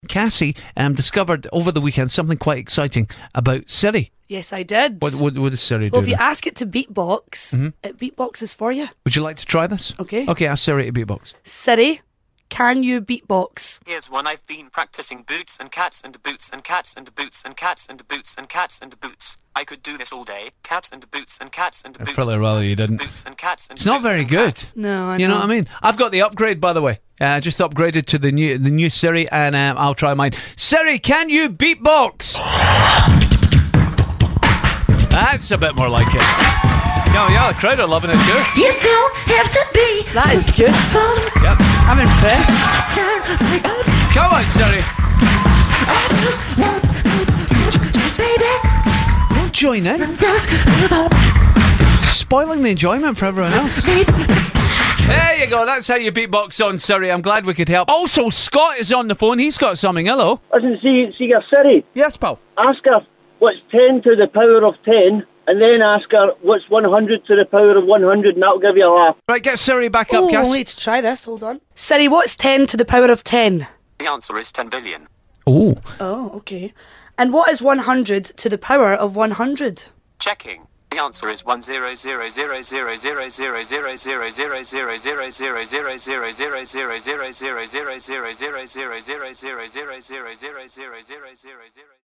'Siri' can beatbox.